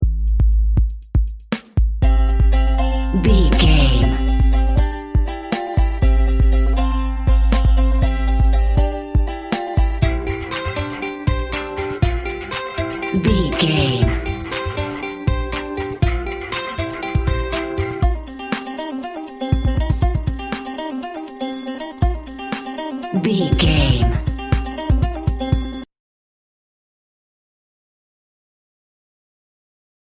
Aeolian/Minor
energetic
lively
bouncy
cheerful/happy
banjo
acoustic guitar
electric guitar
bass guitar
drums
instrumental music